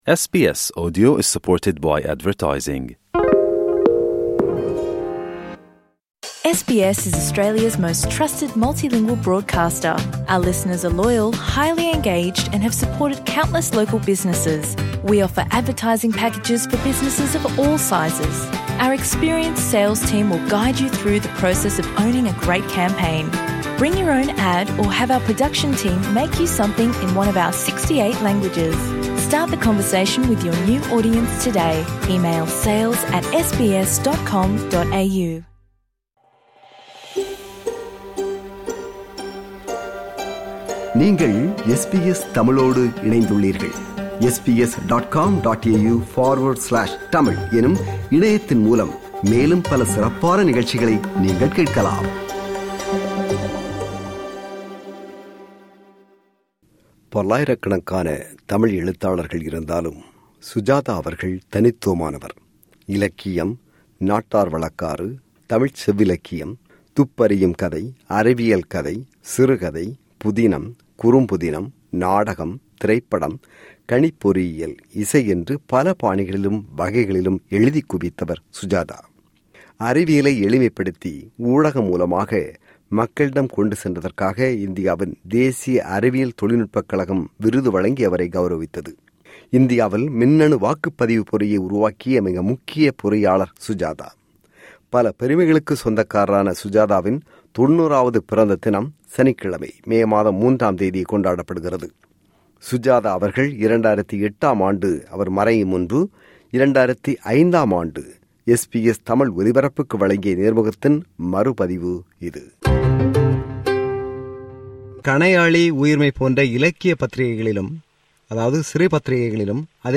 சுஜாதா 90: தமிழ், அறிவியல், திரை, இலக்கியம் குறித்த நேர்முகத்தின் மறு பதிவு
சுஜாதா அவர்கள் 2008 ஆண்டு மறையும் முன்பு 2005 ஆண்டு SBS தமிழ் ஒலிபரப்புக்கு வழங்கிய நேர்முகத்தின் மறு பதிவு இது.